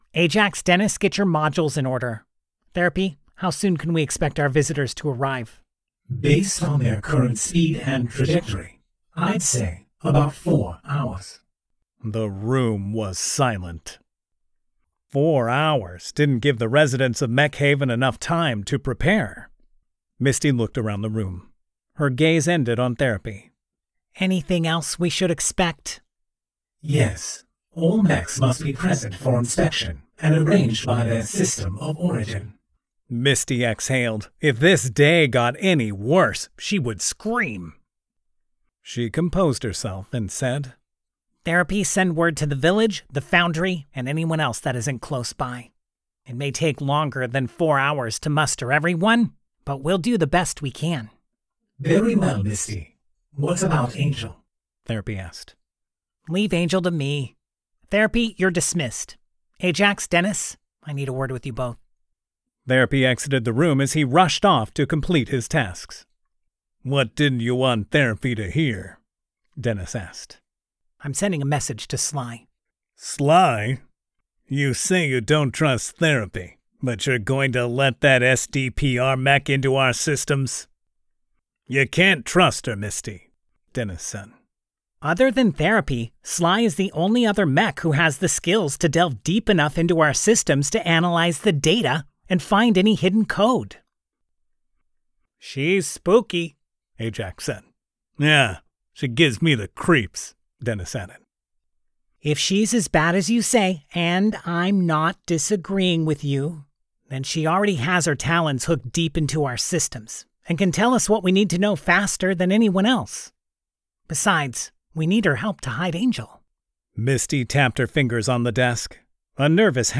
As promised, here’s another excerpt from the Pax Machina audiobook: Main Cast Meets Sly Mech of the Month April’s Mech of the Month is Gridman from SSSS.Gridman !